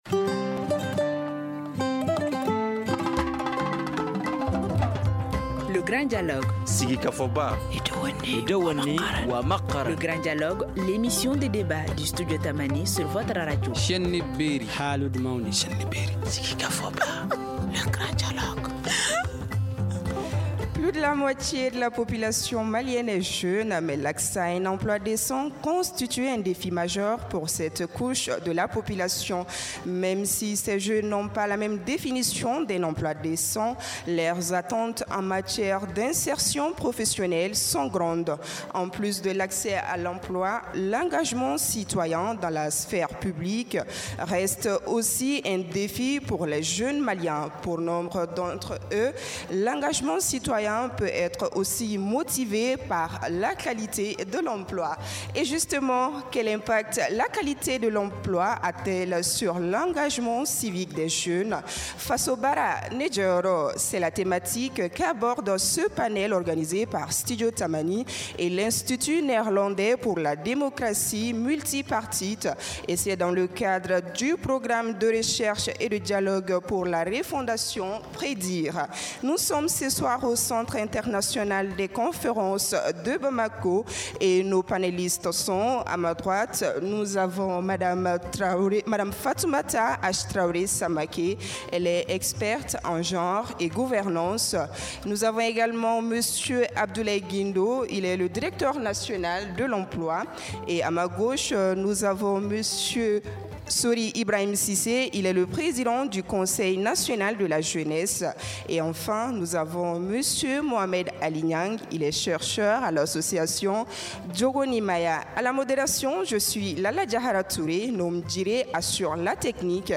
Fasso baara, Ne djô yôrô , c’est la thématique qu’aborde ce panel organisé par Studio Tamani et l’Institut néerlandais pour la démocratie multipartite NIMD.